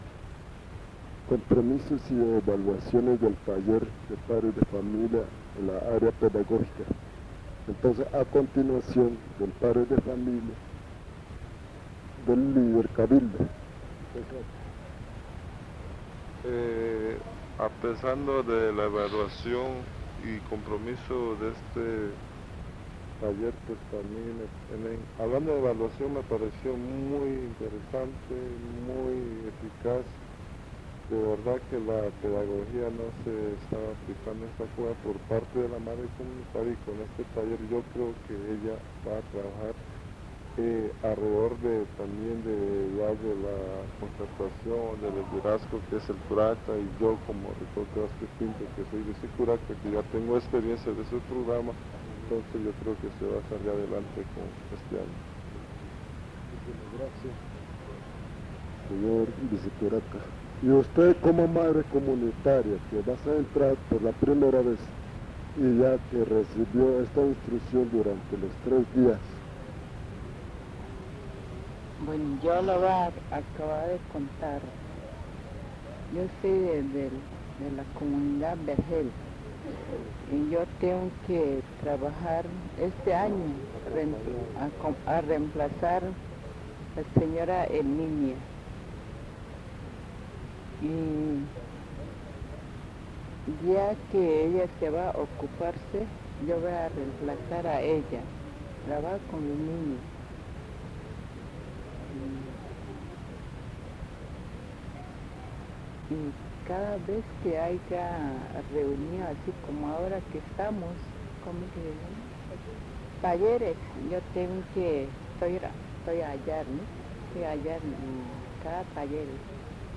Durante la actividad, varios padres de familia expresan su agradecimiento hacia la persona que lideró el taller, valorando especialmente la oportunidad de aprender y compartir experiencias sobre cómo enseñar y orientar a los niños.